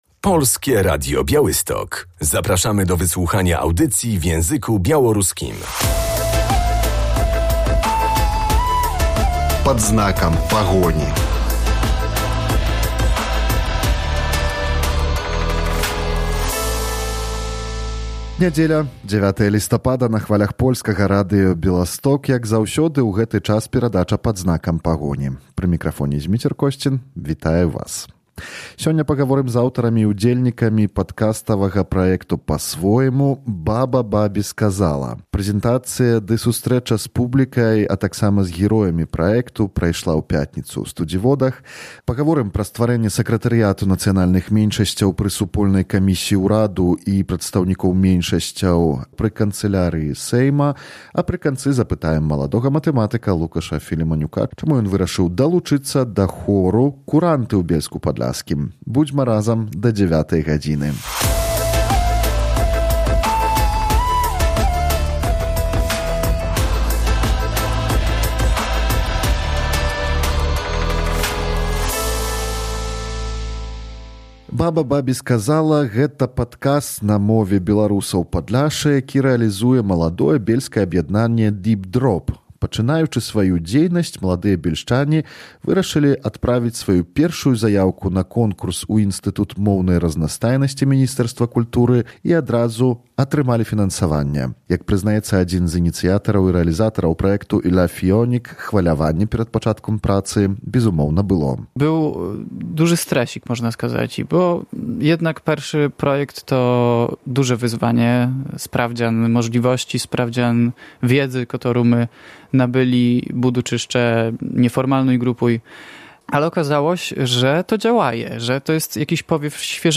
W dzisiejszym wydaniu Pad znakam Pahoni odwiedzamy Studziwody, gdzie odbyła się prezentacja projektu podcastowego „Baba Babi Skazała” realizowanego przez młode bielskie Stowarzyszenie Deep Drop. Twórcy opowiadają o idei projektu, pracy z nagraniami oraz znaczeniu ojczystego języka